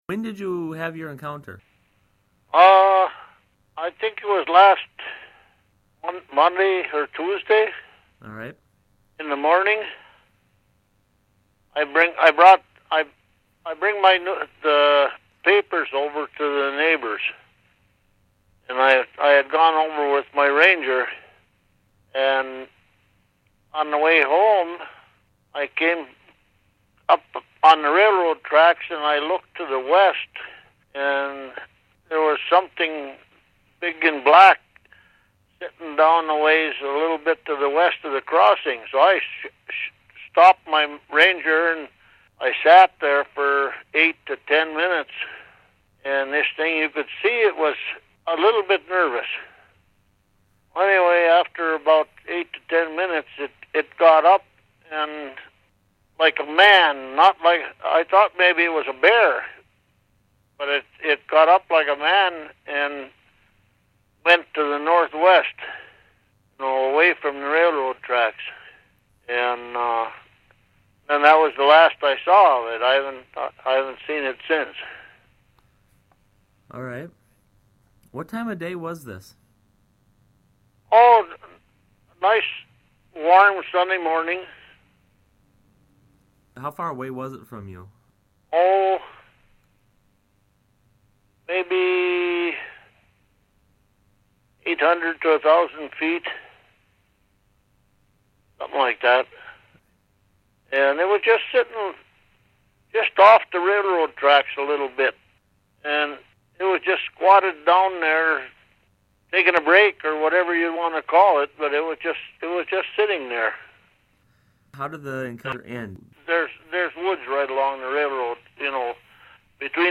The witness is an elderly man who does not have access to a computer. I recorded my interview with him and am posting an excerpt from that interview here in lieu of a written report.